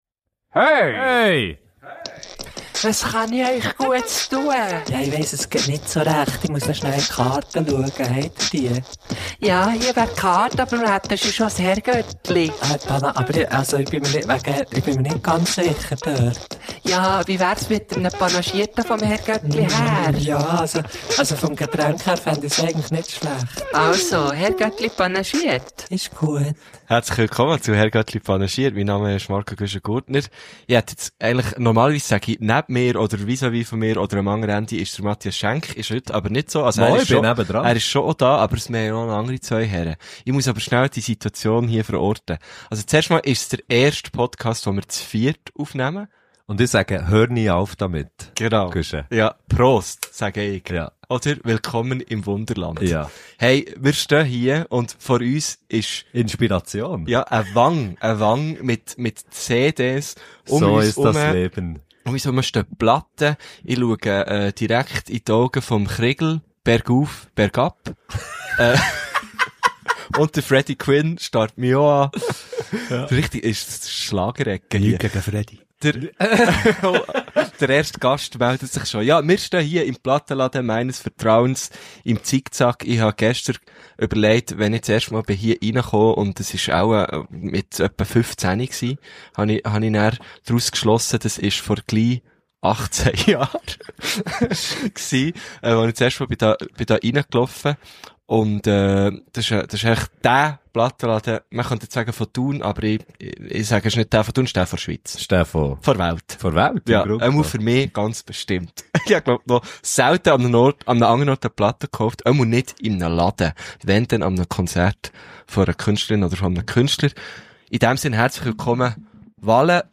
Und wir sind in der Schlager-Ecke gestanden und haben ihnen mit grossen Augen zugehört.